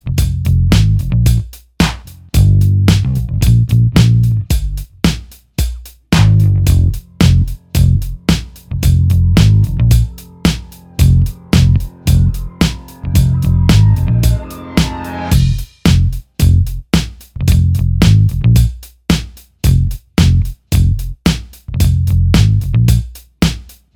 Down One Semitone Rock 3:32 Buy £1.50